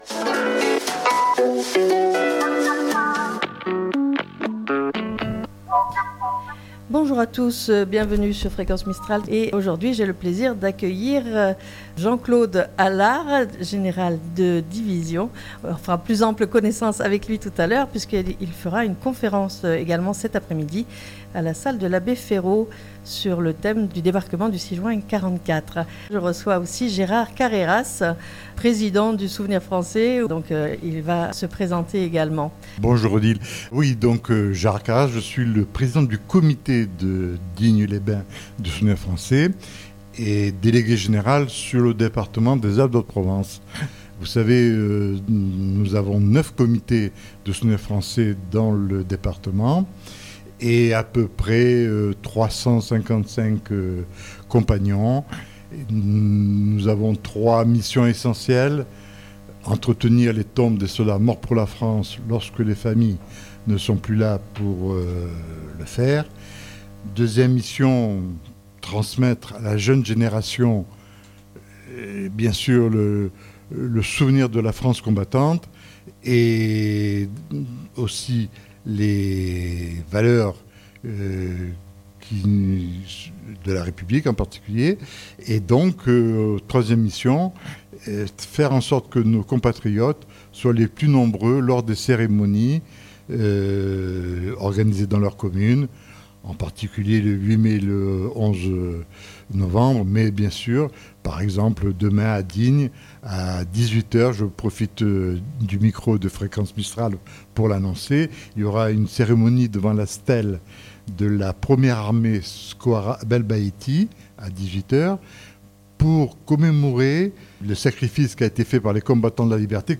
répondent aux questions